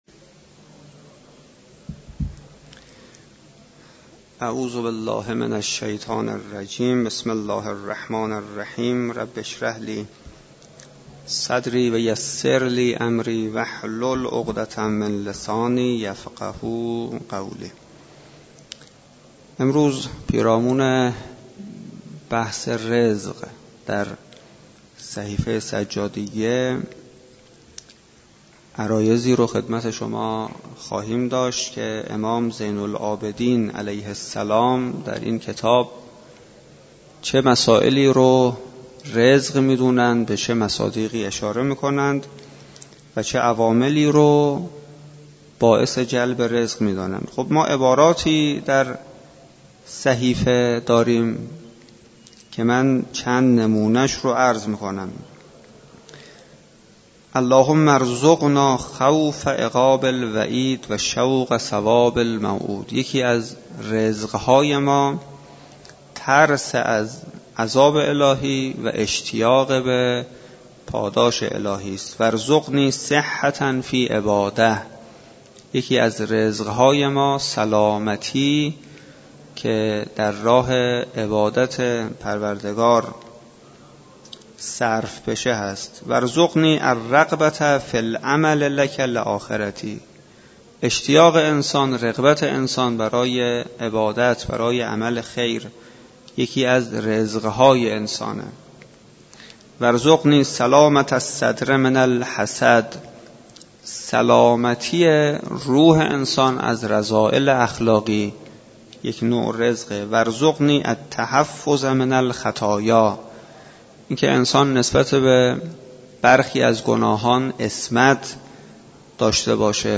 سخنرانی پیرامون رزق و عوامل موثر در جلب رزق بر اساس مفاهیم صحیفه سجادیه
در مسجد دانشگاه کاشان برگزار گردید.